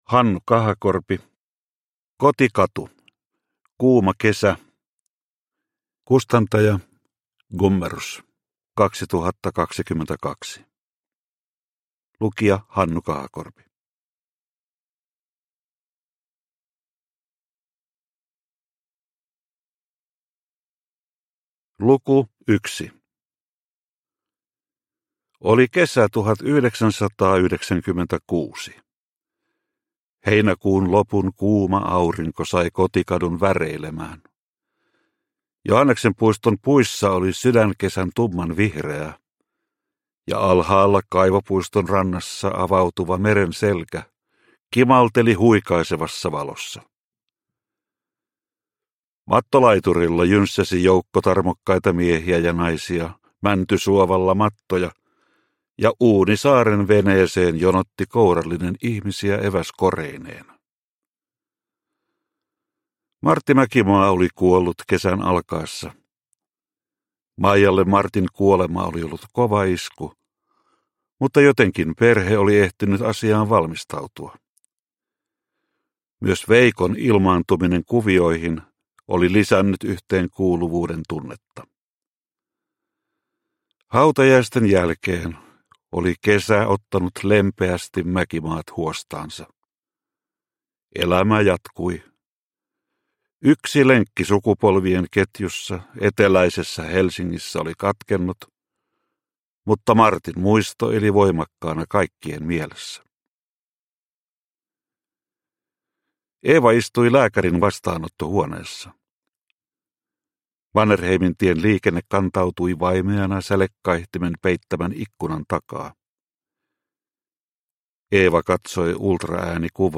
Kotikatu - Kuuma kesä – Ljudbok – Laddas ner